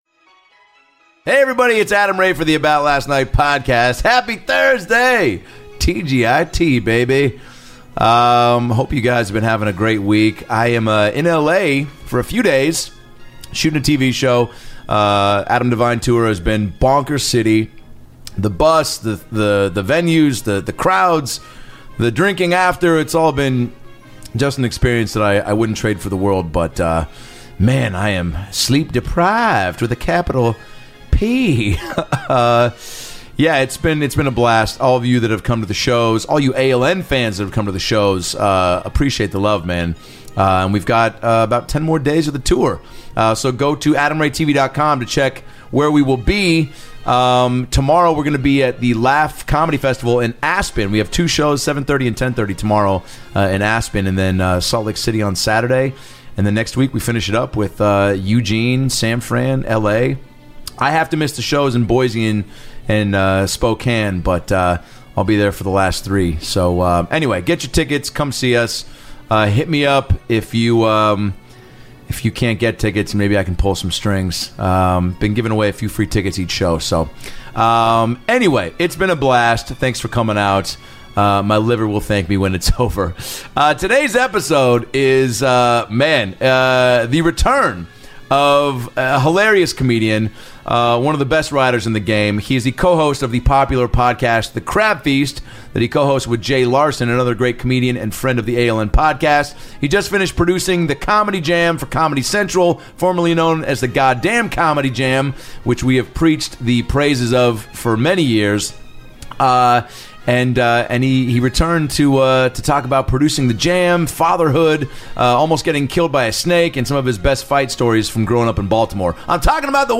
It's a laugh filled ep from top to bottom!!